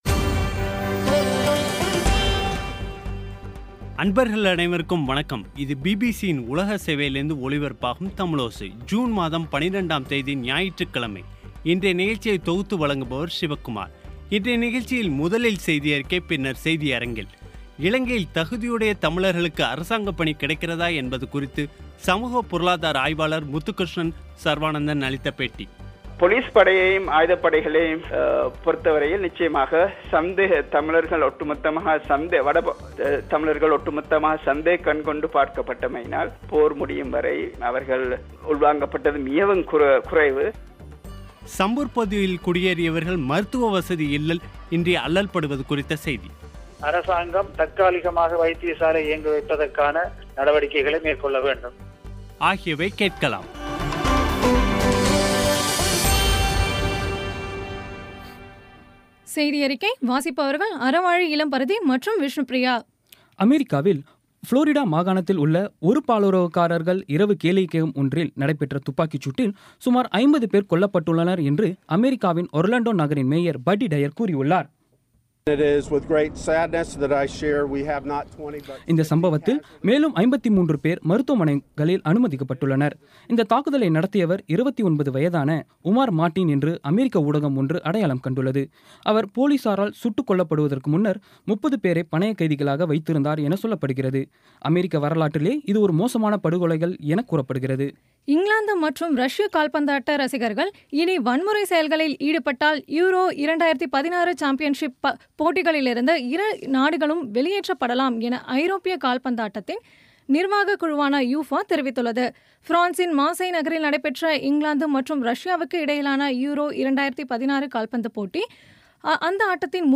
இன்றைய நிகழ்ச்சியில் முதலில் செய்தியறிக்கை பின்னர் செய்தியரங்கில்